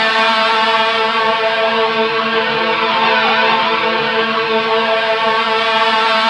Index of /90_sSampleCDs/Roland L-CDX-01/GTR_GTR FX/GTR_Gtr Hits 1
GTR GTR F09L.wav